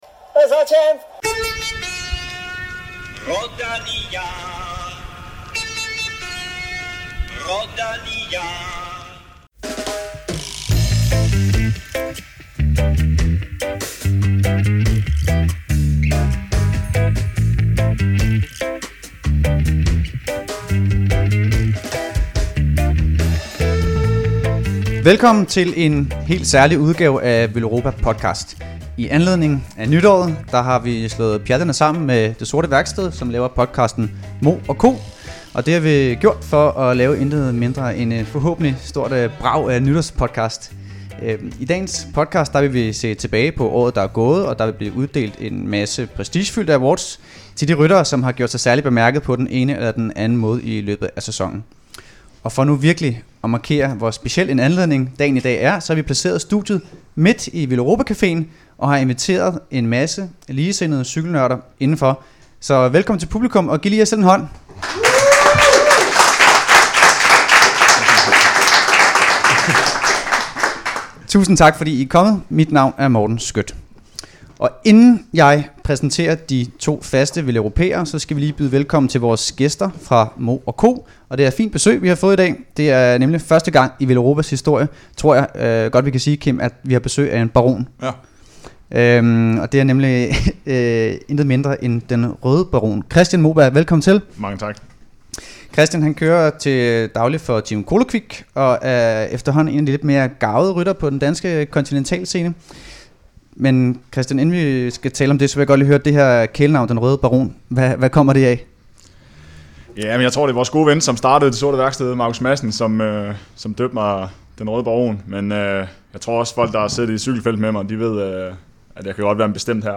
Veloropa og Mo&Co siger farvel til 2018 og goddag til 2019 i denne fælles-podcast foran et veloplagt publikum på Veloropa caféen. Der bliver uddelt priser til årets bedste indenfor en række kategorier.